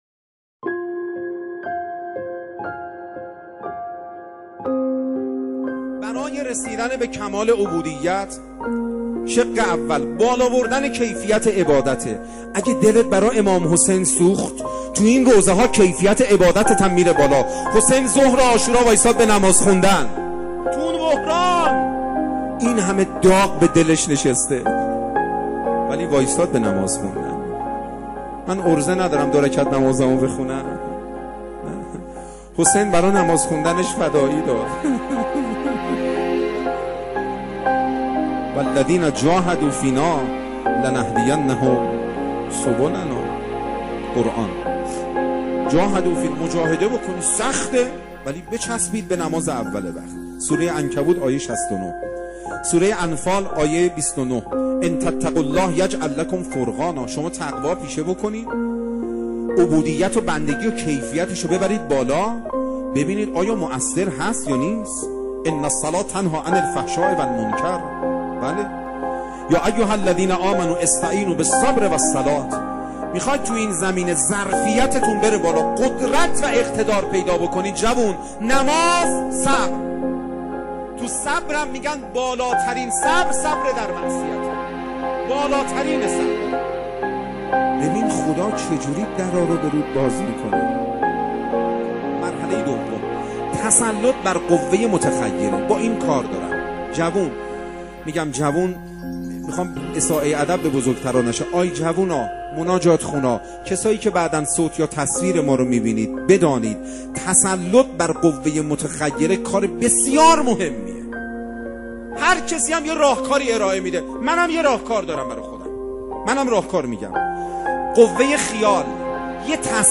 روضه ابا عبدالله-راه دوری از گناه.mp3
روضه-ابا-عبدالله-راه-دوری-از-گناه.mp3